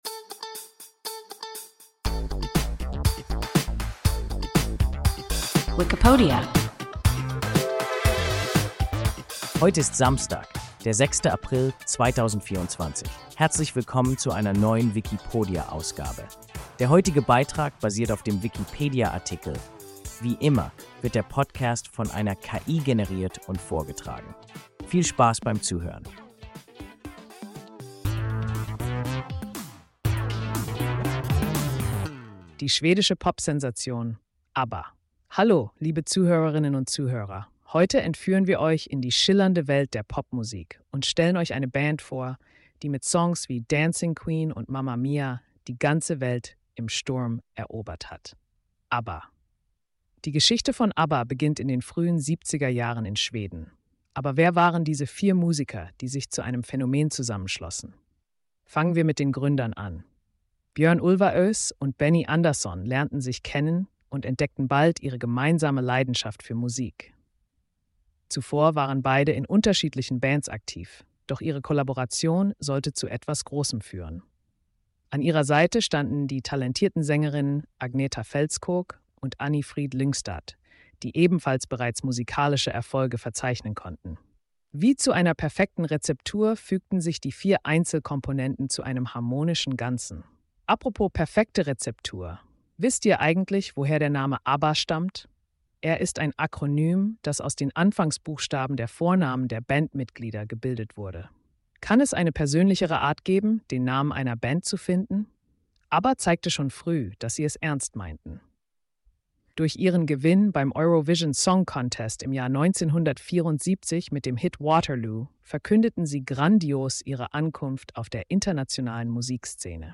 ABBA – WIKIPODIA – ein KI Podcast